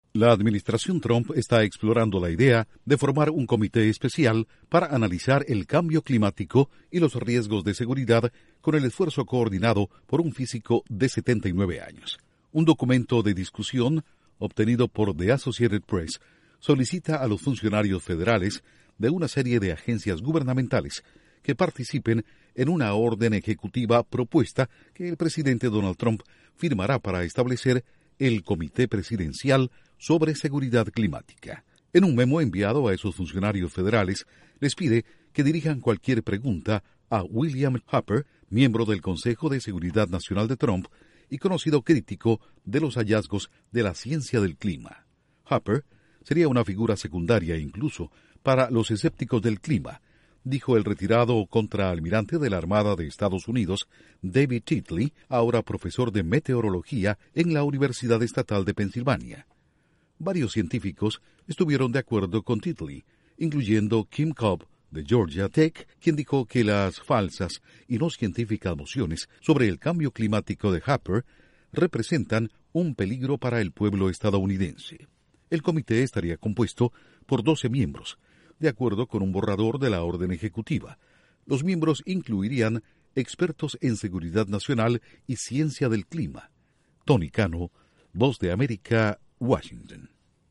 La Casa Blanca explora formar un comité para analizar el cambio climático y los riesgos a la seguridad .Informa desde la Voz de América en Washington